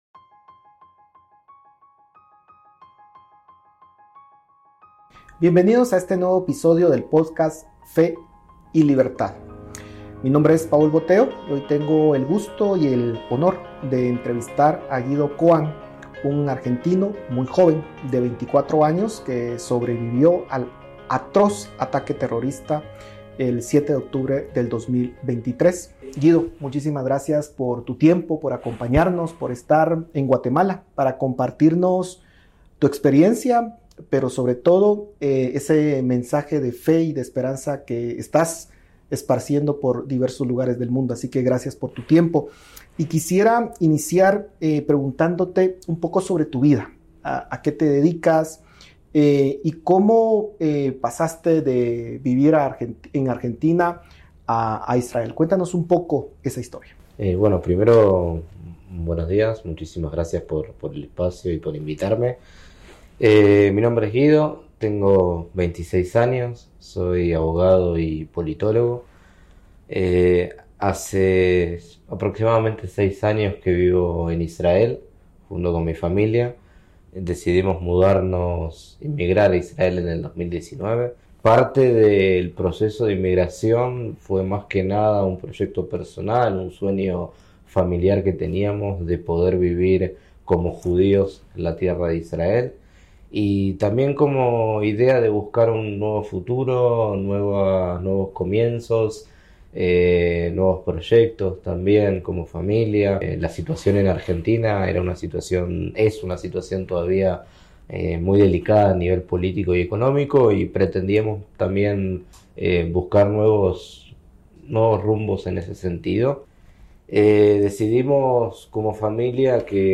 Sobrevivir al 7 de octubre. Una conversación